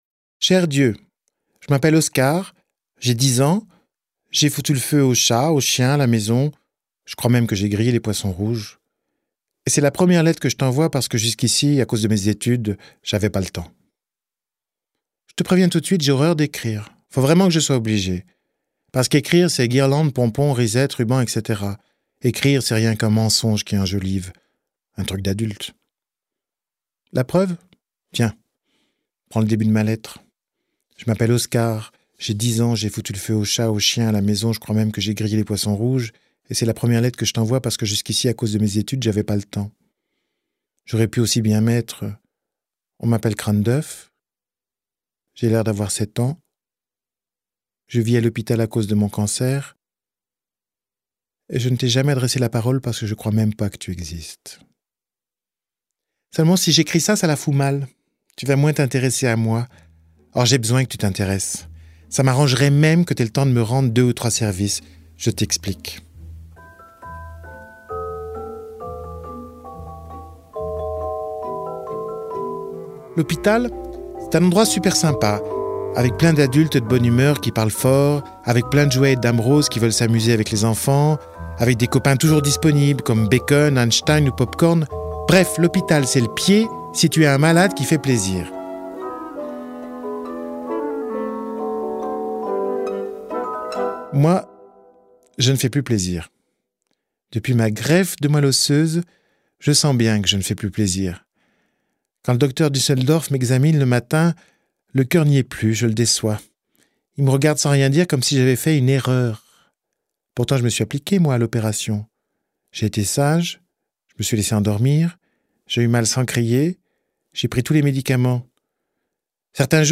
Lire un extrait Eric-Emmanuel Schmitt Oscar et la dame rose Audiolib Date de publication : 11/10/2017 Pour rendre plus doux les derniers moments du petit malade, Mamie Rose propose un jeu à Oscar : vivre chaque jour comme s'il représentait dix ans. Interprété par l’auteur, accompagné par quelques extraits tintinnabulants du Casse-Noisette et le bal des souris de Tchaïkovski, ce conte a la fraîcheur insolente de l’enfance et raconte la relation particulière tissée entre une vieille dame et un jeune garçon, la puissance de la méditation et l’incroyable force de vie d’Oscar.